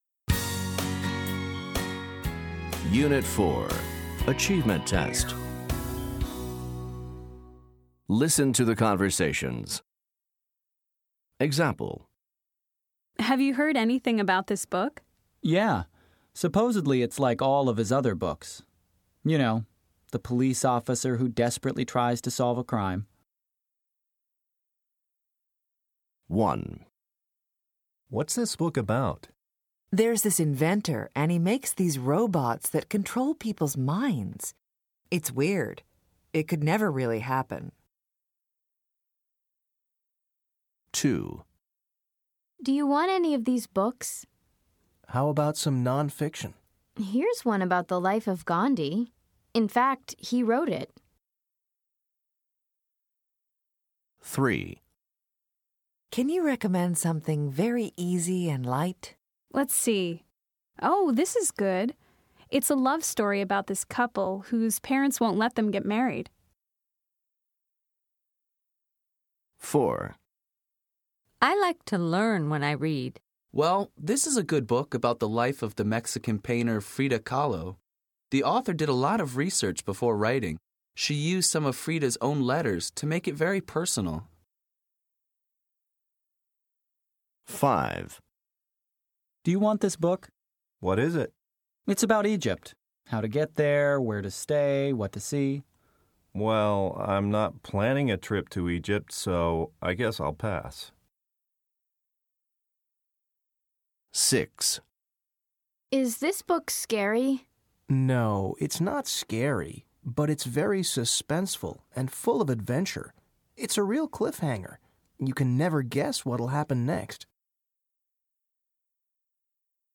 Listen to the conversations. Then write the type of book each person talks about.